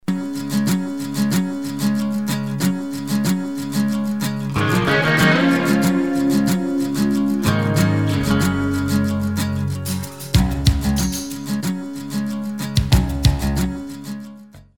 Country Music Samples
Country 12a